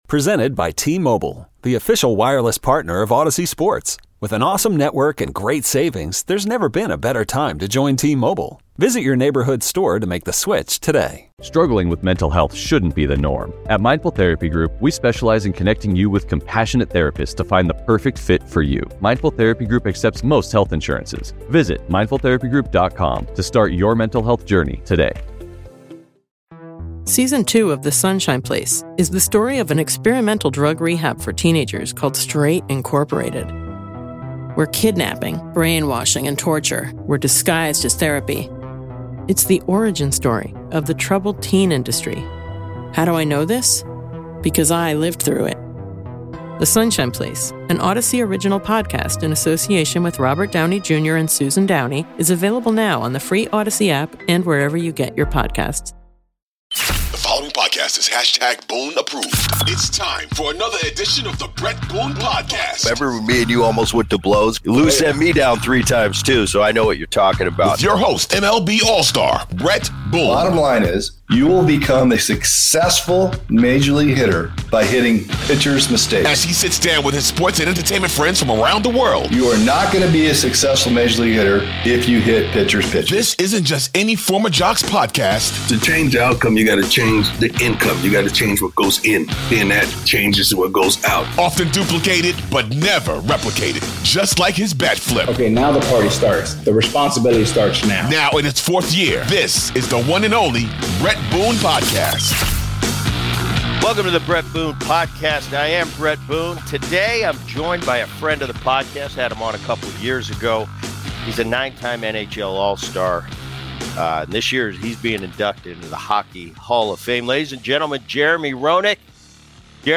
Catch the show live Monday through Friday (10 a.m.- 2 p.m. CT) on 670 The Score, the exclusive audio home of the Cubs and the Bulls, or on the Audacy app.